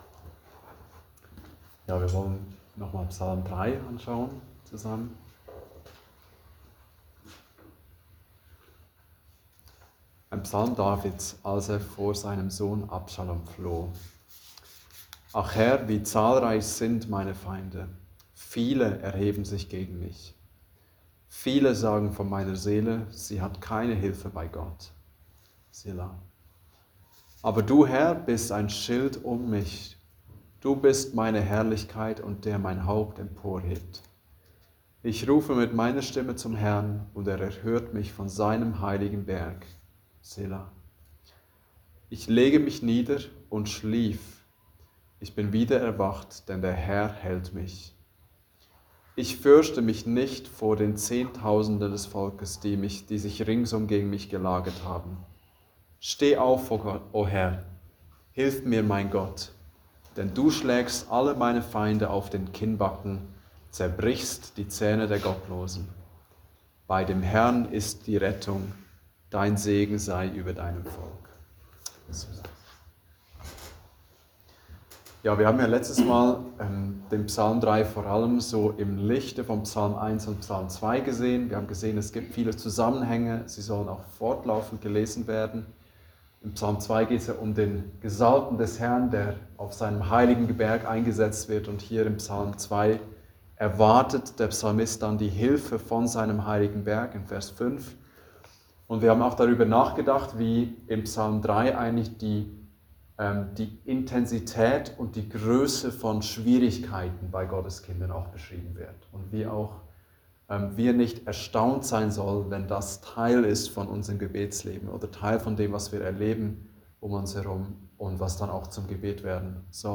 Gottes sichere Hilfe (Andacht Gebetsstunde)